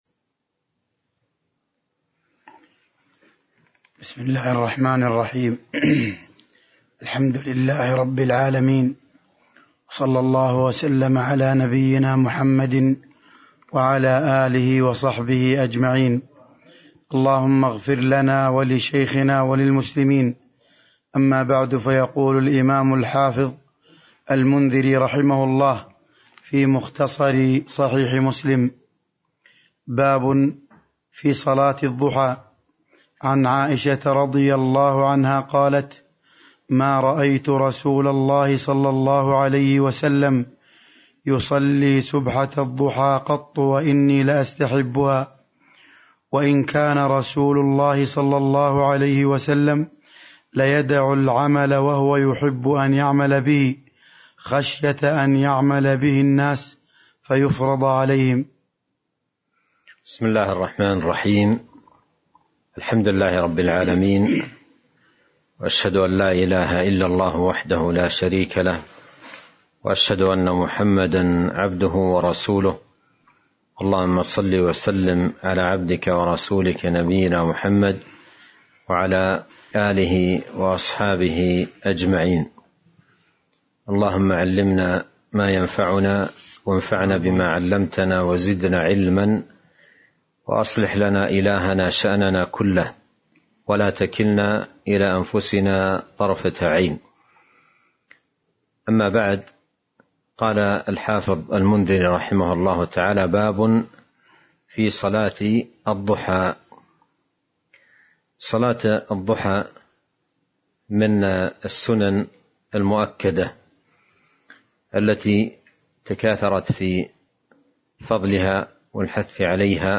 تاريخ النشر ٥ جمادى الآخرة ١٤٤٢ هـ المكان: المسجد النبوي الشيخ